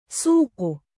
音标: /suːq/